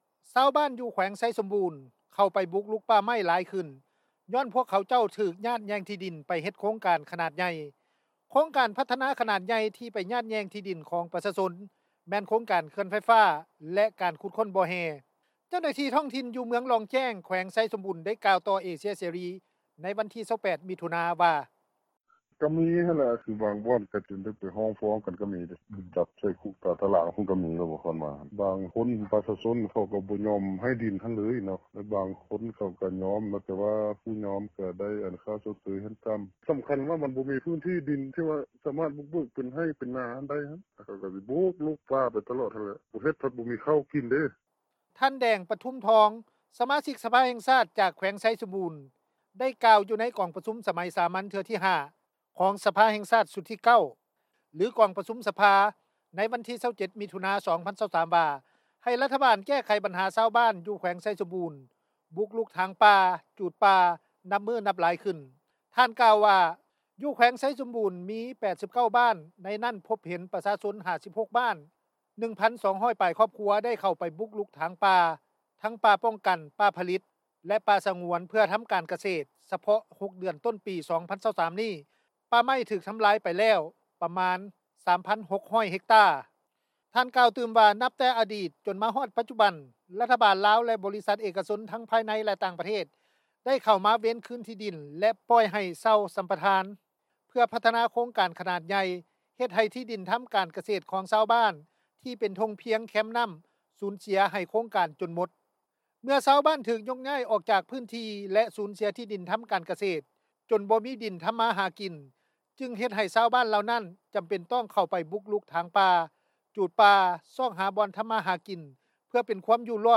ເຈົ້າໜ້າທີ່ທ້ອງຖິ່ນ ຢູ່ເມືອງລ່ອງແຈ້ງ ແຂວງ ໄຊສົມບູນ ໄດ້ກ່າວຕໍ່ວິທຍຸ ເອເຊັຽ ເສຣີ ໃນວັນທີ 28 ມິຖຸນາ ວ່າ:
ຊາວບ້ານ ເມືອງອະນຸວົງ ແຂວງໄຊສົມບູນ ໄດ້ກ່າວວ່າ: